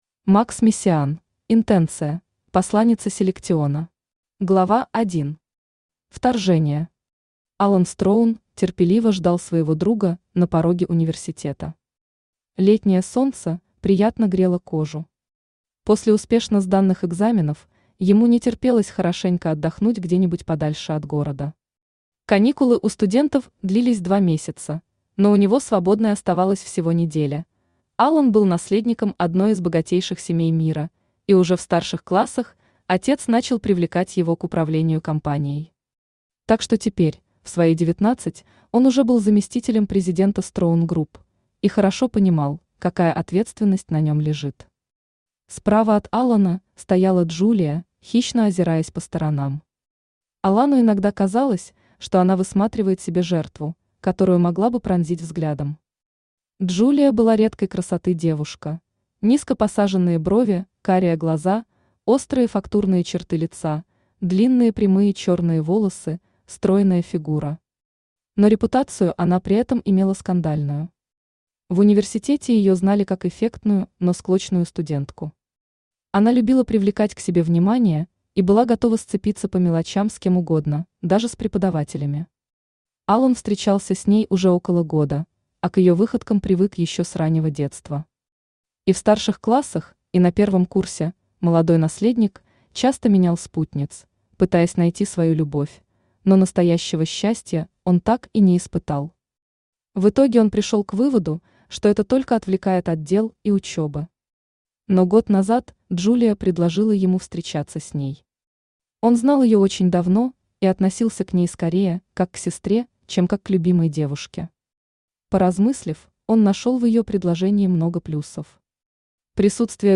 Аудиокнига Интенция. Посланница Селектиона | Библиотека аудиокниг
Посланница Селектиона Автор Макс Мессиан Читает аудиокнигу Авточтец ЛитРес.